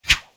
Close Combat Swing Sound 38.wav